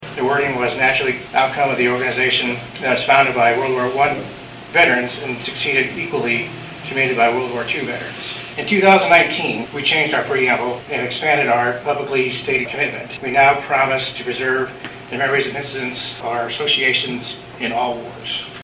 The ceremony, which was moved indoors due to rain Monday, honored all the fallen soldiers from all of the wars throughout this country’s history.